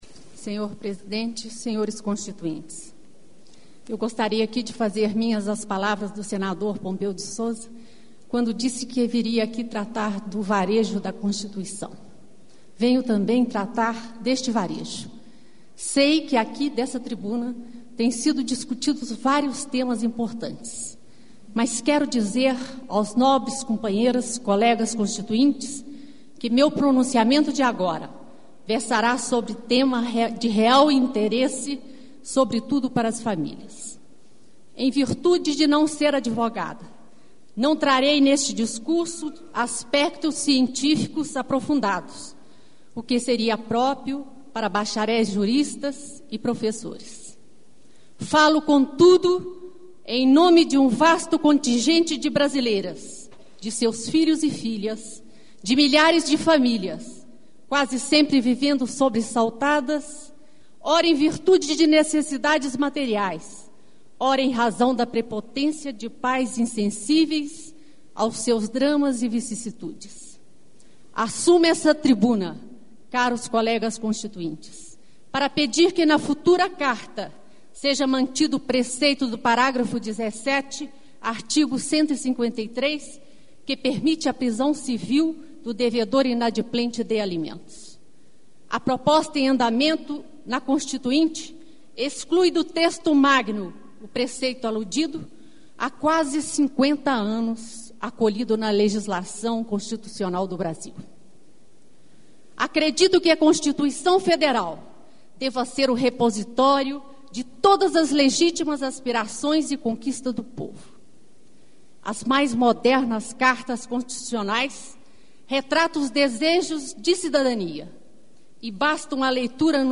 - Discurso pronunciado em 14 de agosto de 1987 – Devedor inadimplente de alimentosDiscute, em primeiro turno, do Projeto de constituição. Defende a manutenção, na futura Carta Magna, do preceito que permite a prisão civl do devedor inadimplente de alimentos.